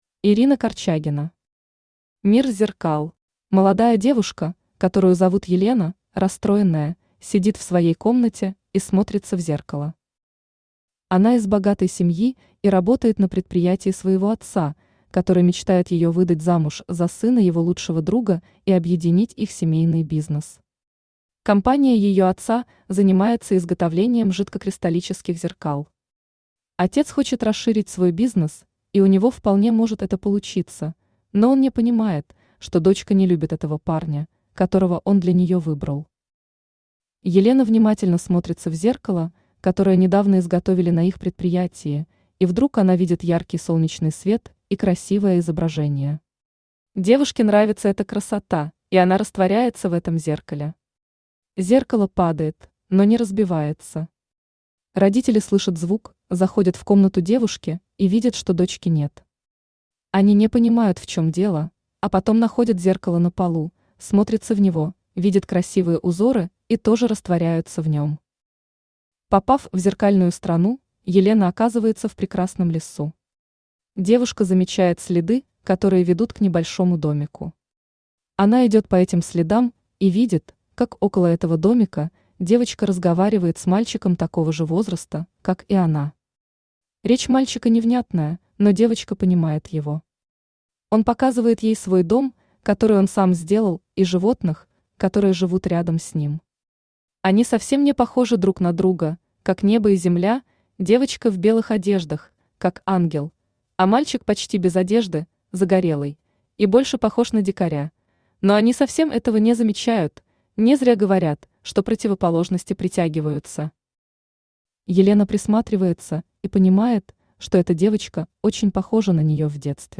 Аудиокнига Мир зеркал | Библиотека аудиокниг
Aудиокнига Мир зеркал Автор Ирина Юрьевна Корчагина Читает аудиокнигу Авточтец ЛитРес.